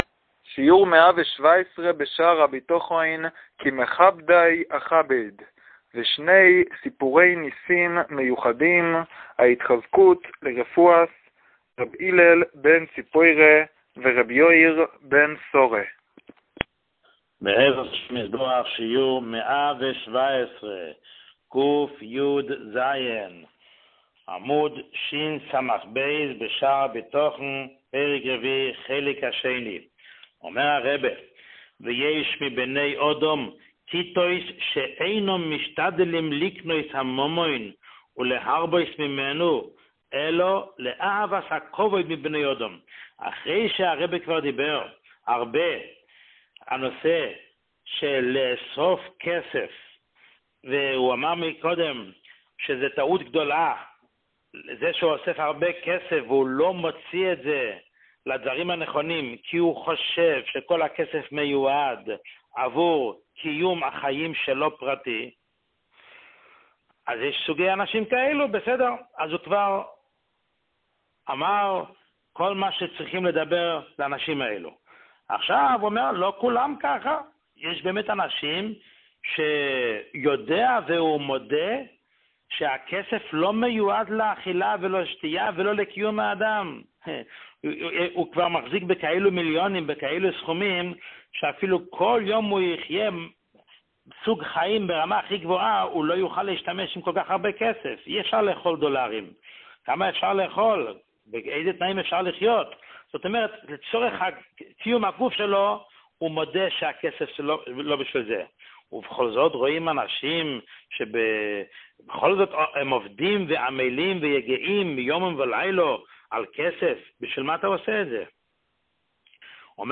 שיעור 117